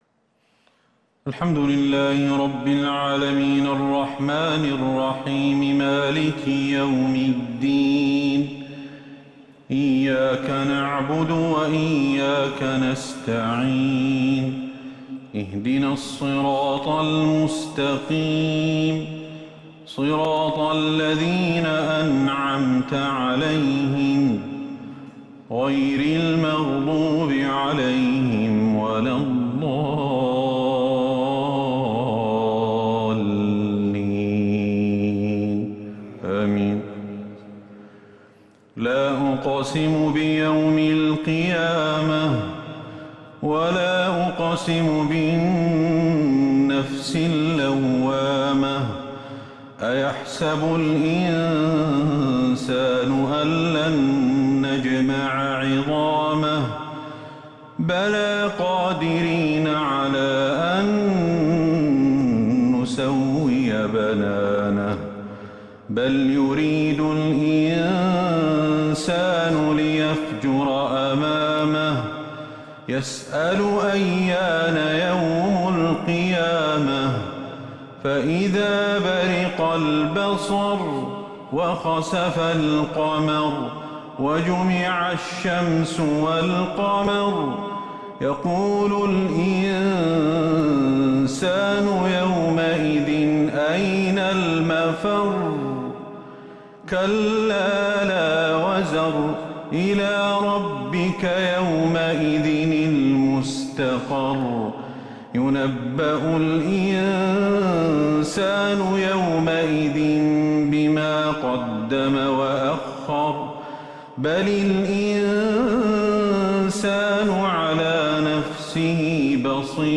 صلاة التراويح l ليلة 29 رمضان 1442 | من سورة القيامة إلي سورة النازعات | taraweeh prayer The 29th night of Ramadan 1442H | > تراويح الحرم النبوي عام 1442 🕌 > التراويح - تلاوات الحرمين